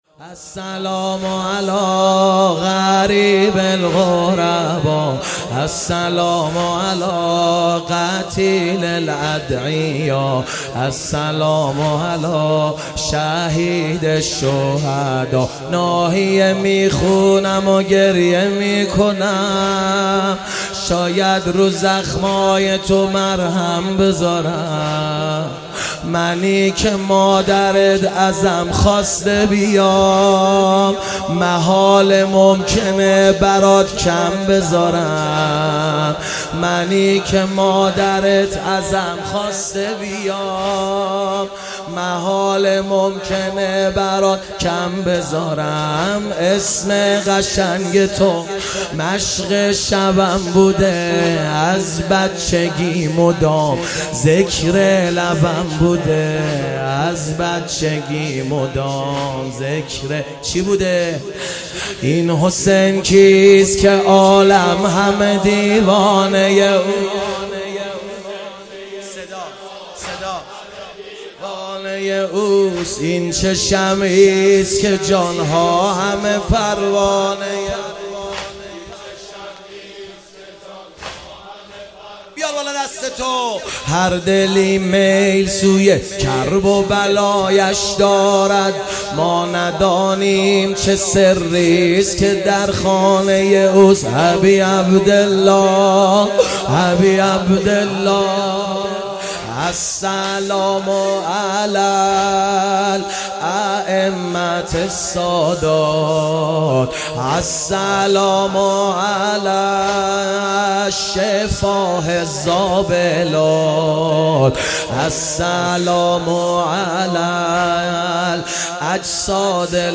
مداحی جدید
شب چهارم محرم ۱۳۹۹هیئت حیدریون اصفهان